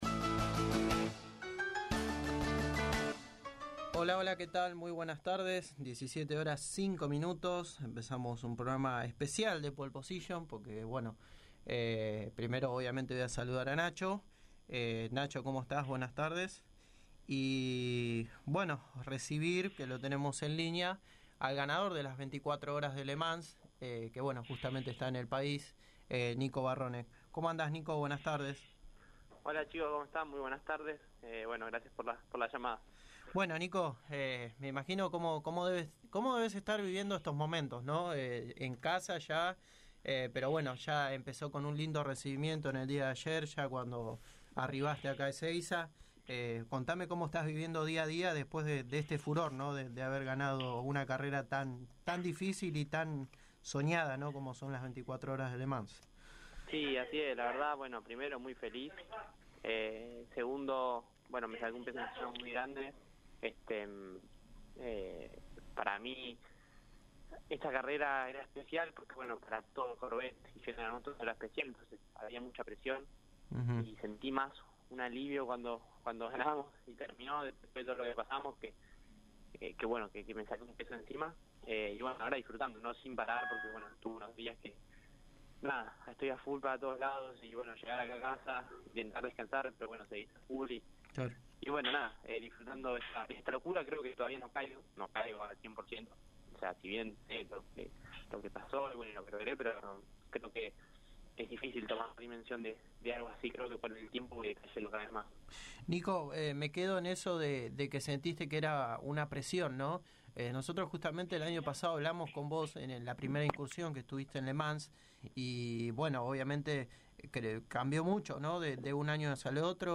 El piloto de Ingeniero Maschwitz pasó por los micrófonos de Pole Position y habló del triunfo obtenido en las 24 horas de Le Mans. Habló de como está viviendo estos días revolucionarios después de las victoria y aclaró la situación de los rumores sobre un posible futuro dentro de IndyCar.